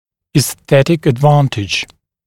[iːs’θetɪk əd’vɑːntɪʤ] [и:с’сэтик эд’ва:нтидж] эстетическое преимущество (US esthetic advantage)